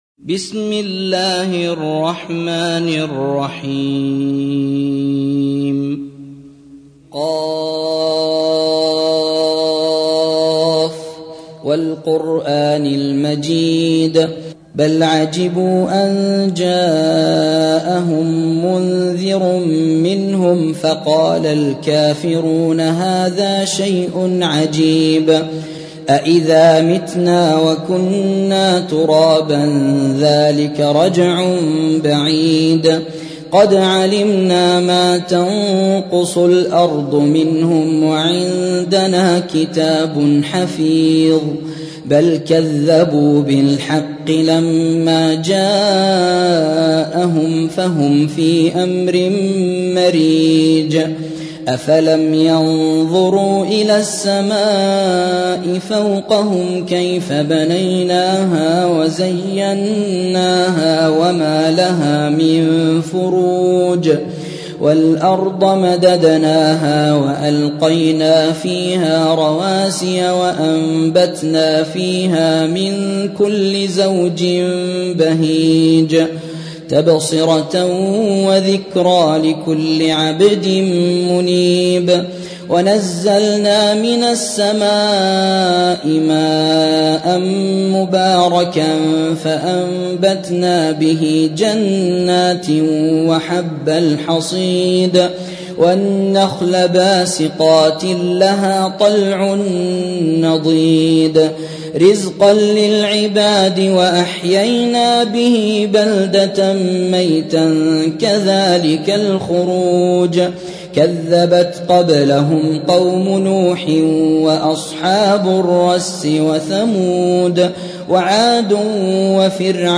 50. سورة ق / القارئ